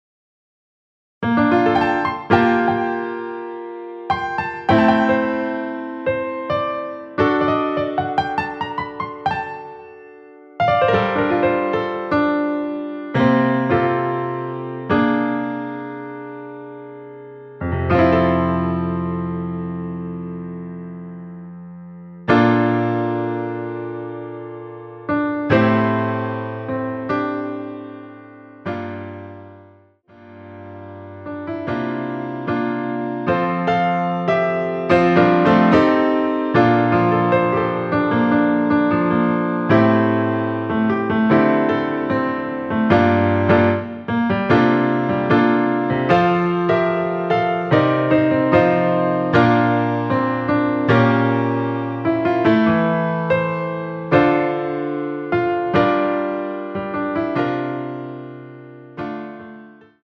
원키에서(+5)올린 MR입니다.
앞부분30초, 뒷부분30초씩 편집해서 올려 드리고 있습니다.